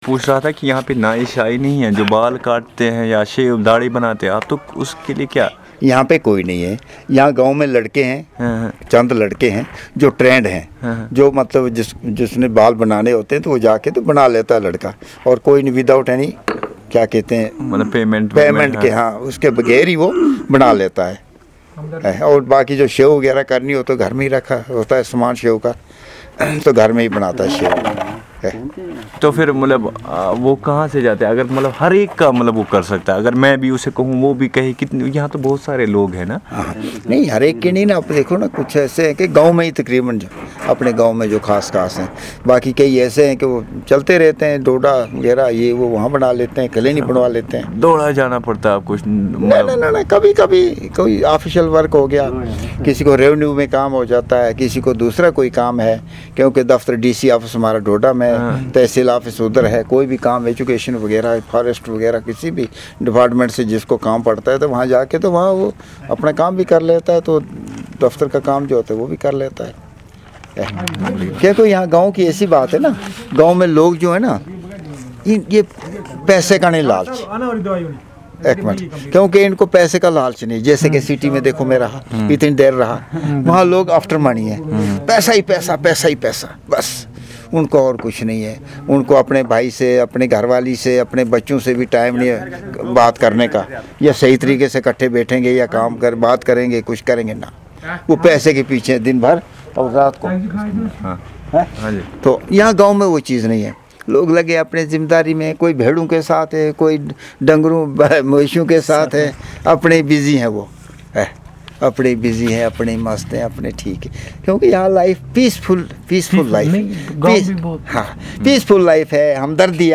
Conversation on and description of the life in the village